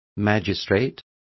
Complete with pronunciation of the translation of magistrates.